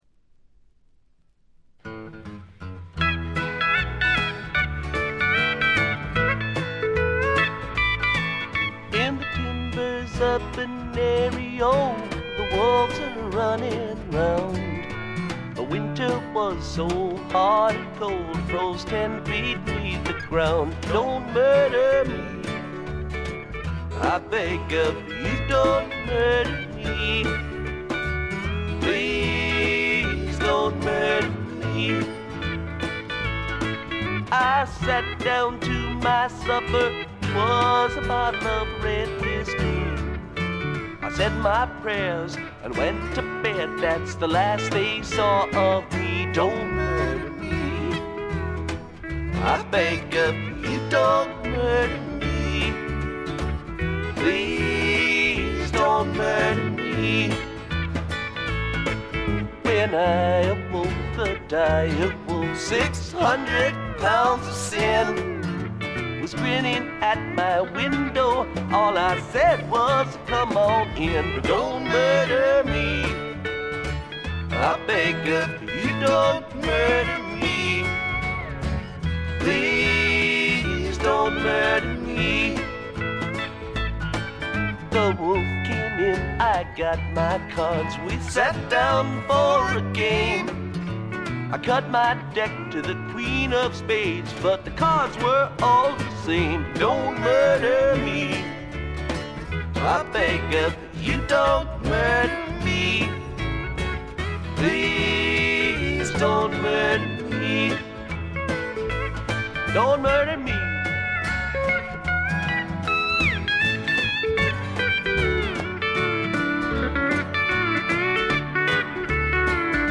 軽微なチリプチ程度。
試聴曲は現品からの取り込み音源です。